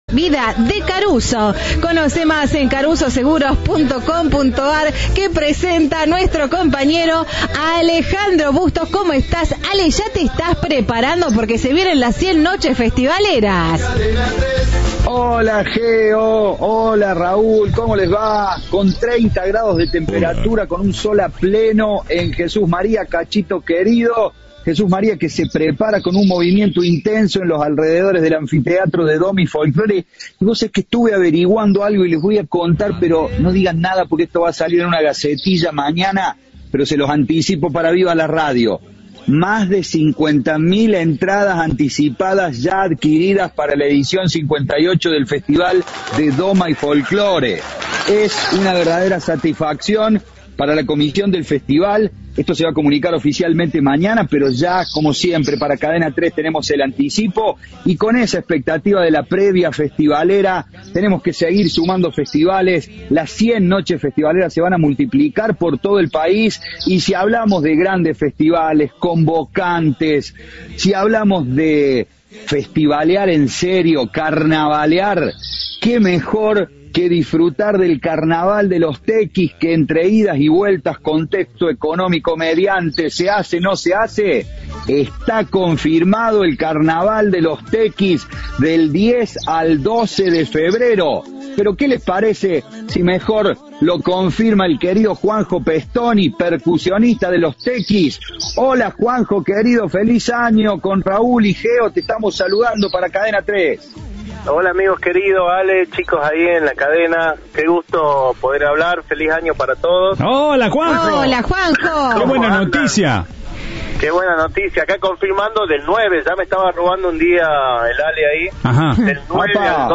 en diálogo con Cadena 3